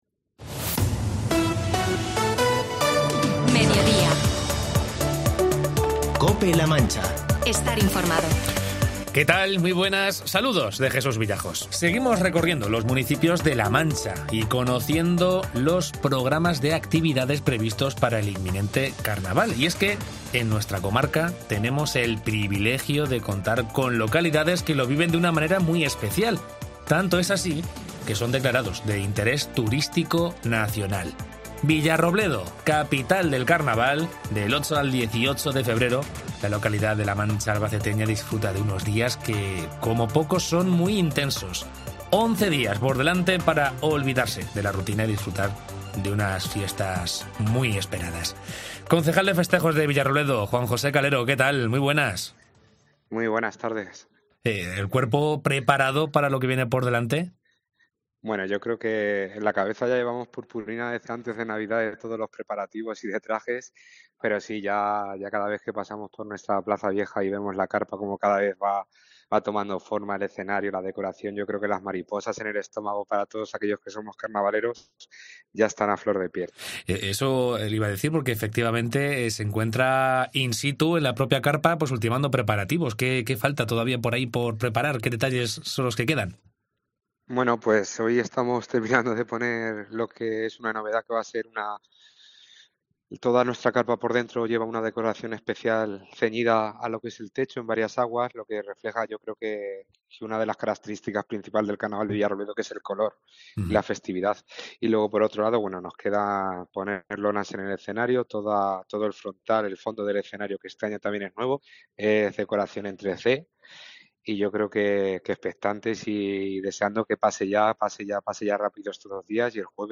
Entrevista con Juan José Calero, concejal de Festejos de Villarrobledo
AUDIO: En Mediodía Cope La Mancha entrevistamos al concejal de festejos del Ayuntamiento de Villarrobledo, Juan José Calero, horas previas al...